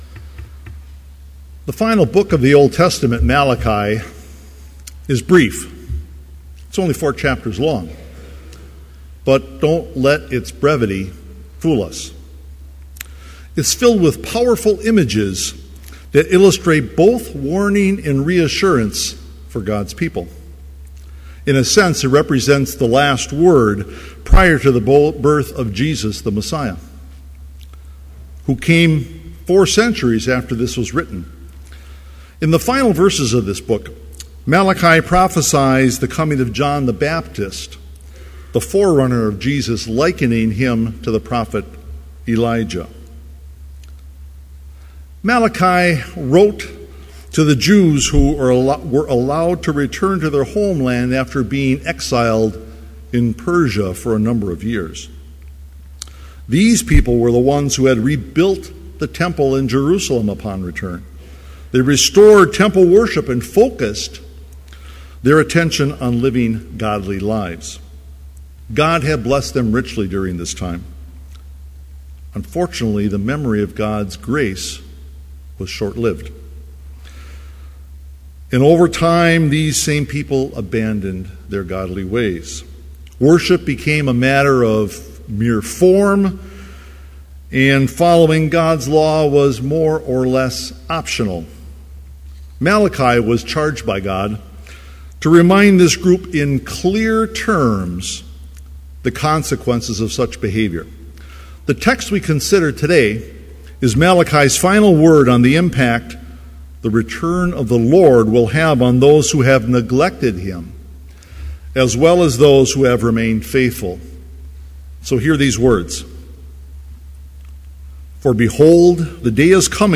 Complete service audio for Chapel - December 13, 2013